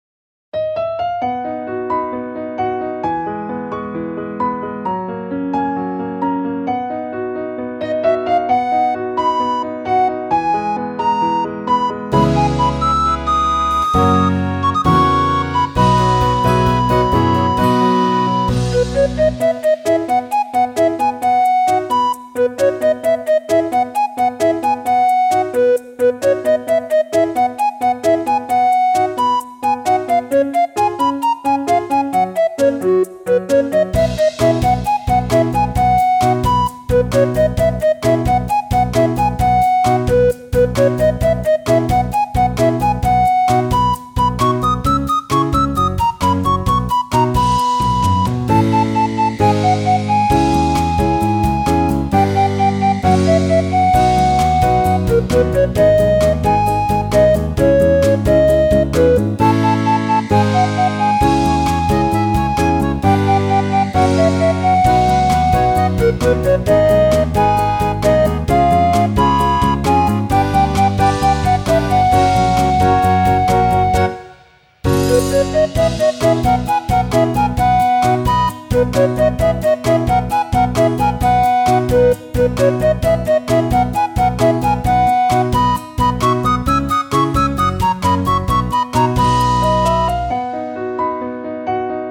ogg(L) かわいい オープニング ポップ
リコーダーが可愛い、明るいオープニングテーマ風楽曲。